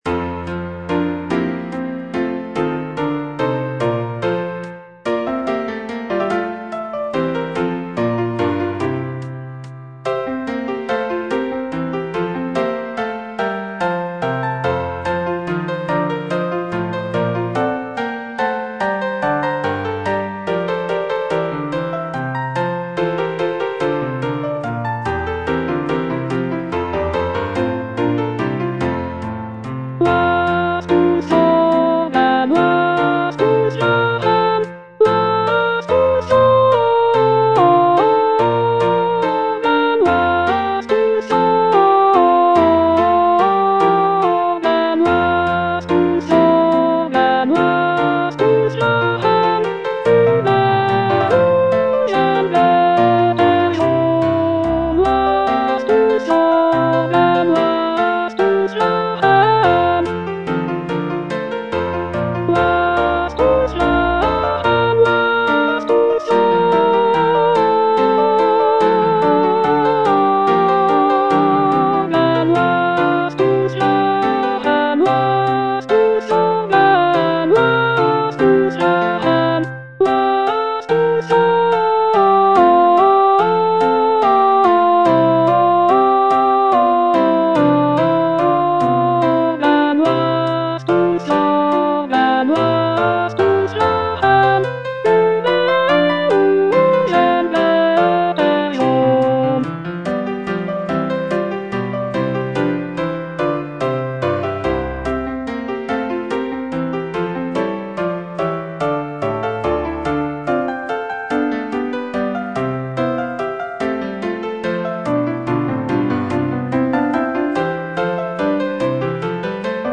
The cantata features a celebratory and joyful tone, with arias and recitatives praising the prince and his virtues. It is scored for soloists, choir, and orchestra, and showcases Bach's mastery of counterpoint and vocal writing.